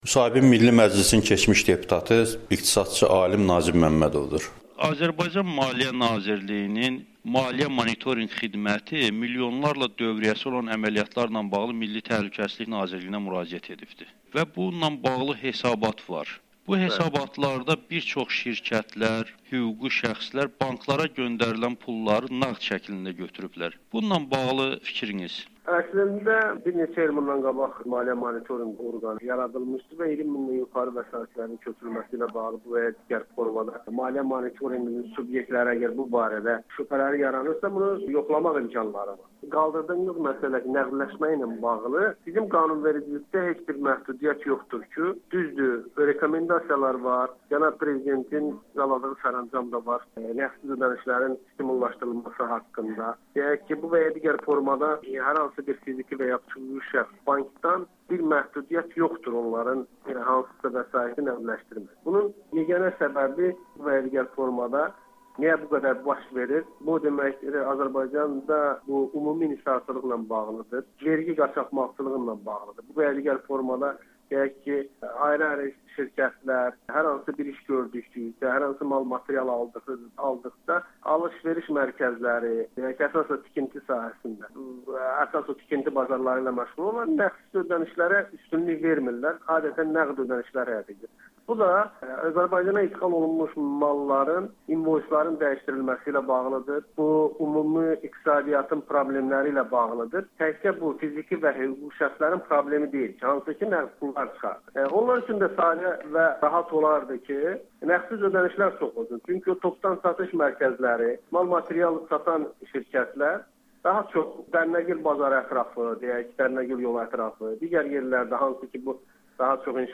Nazim Məmmədov: QHT-lərlə bağlı cinayət işi Azərbaycanın imicinə çox ciddi zərbə vurur [Audio-müsahibə]
Milli Məclisin keçmiş deputatının Amerikanın Səsinə müsahibəsi